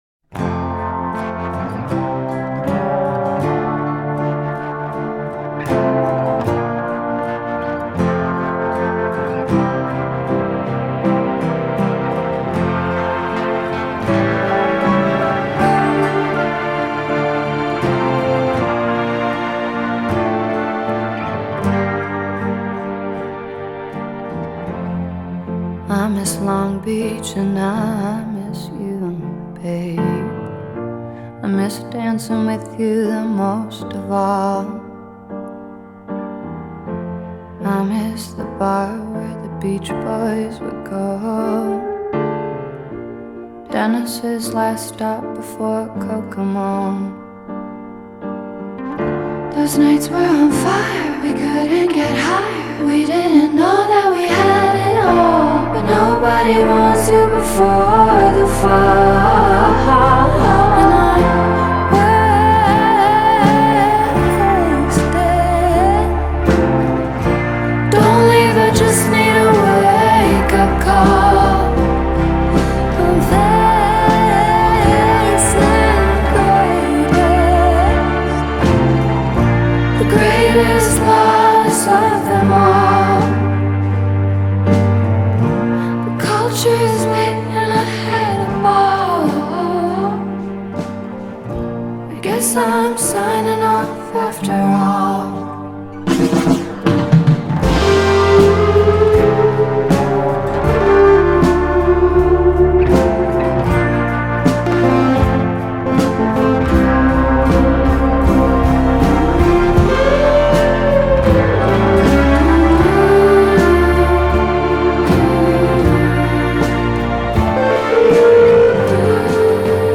Introspective, reflective and melancholic to the max